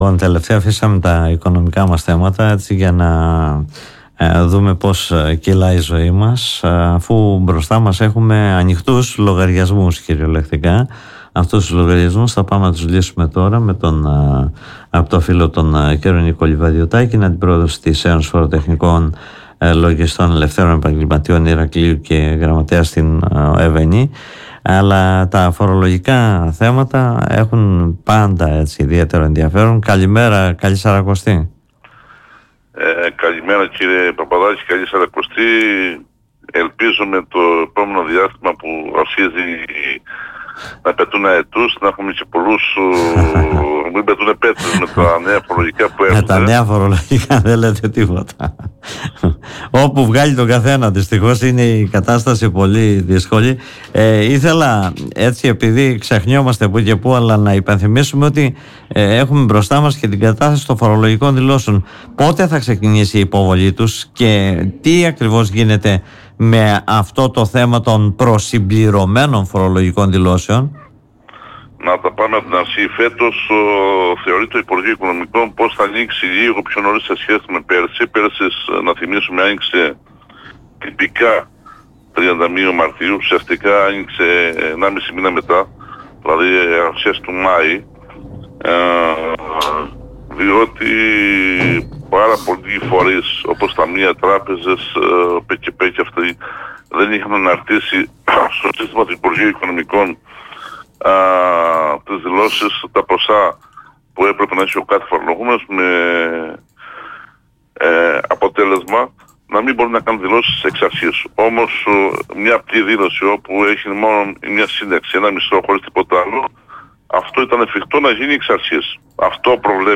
μιλώντας στην εκπομπή “Δημοσίως” του politica 89.8